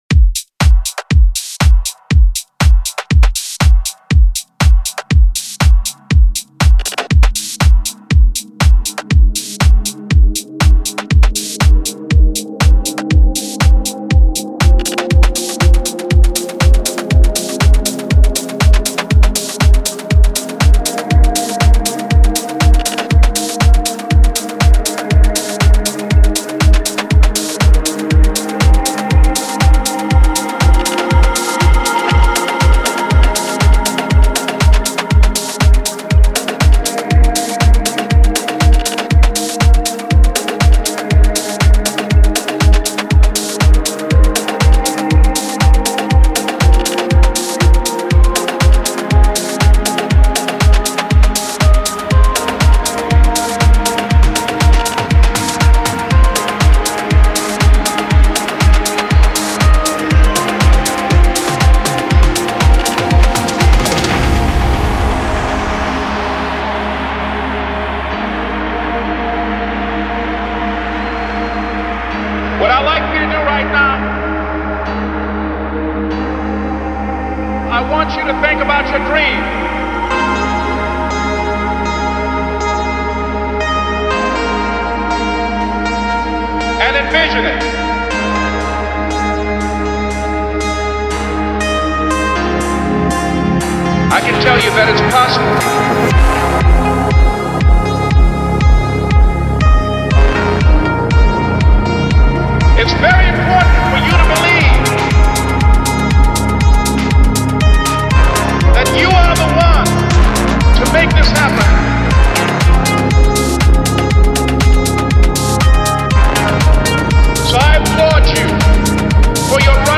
это атмосферная трек в жанре прогрессив-хаус